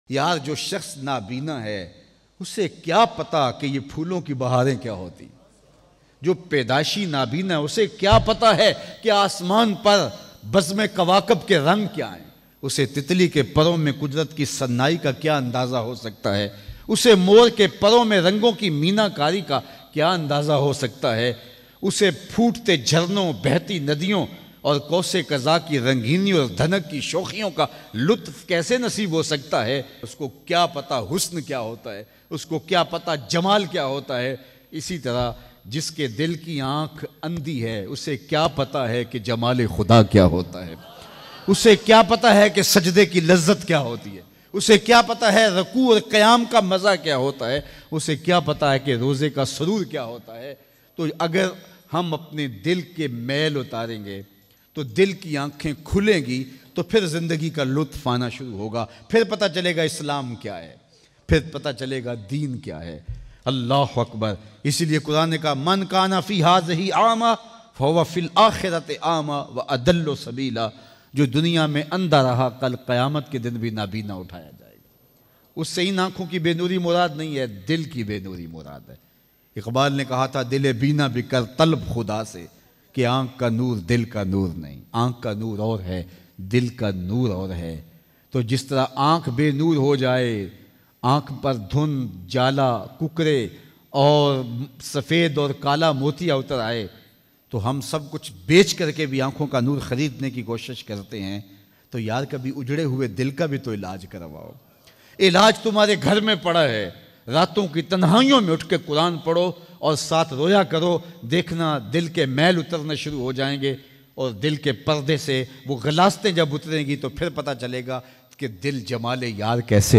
Dil ki Tarap ko Sukoon me badalne Bayan MP3